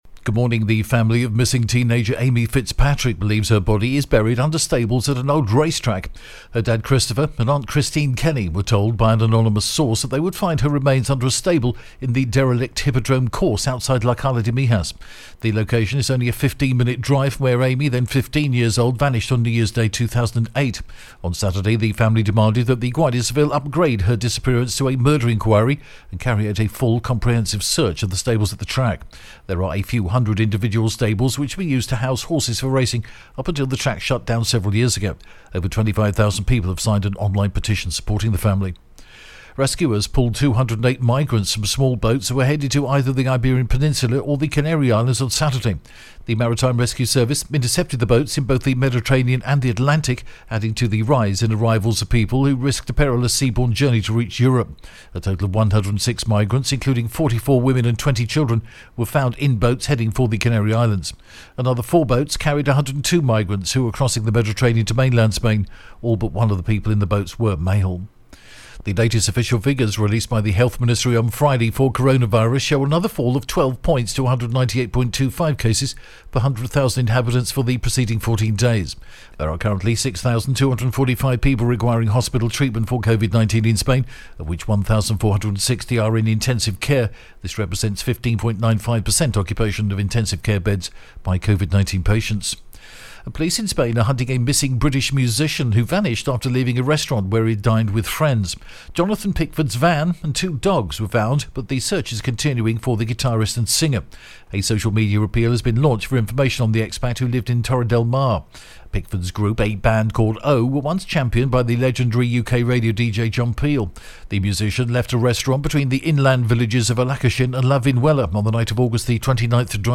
The latest Spanish news headlines in English: 6th September 2021 AM